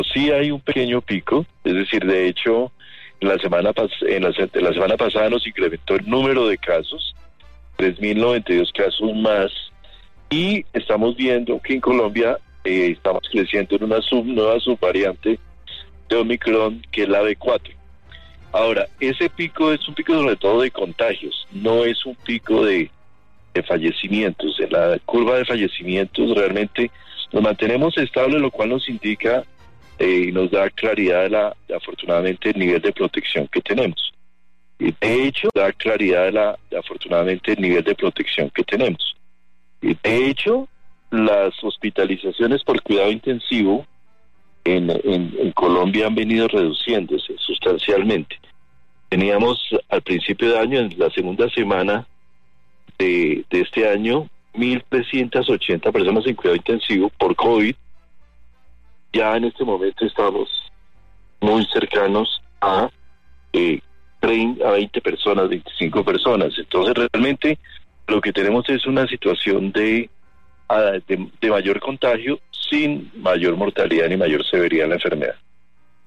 Video del ministro de Salud y Protección Social, Fernando Ruiz Gómez.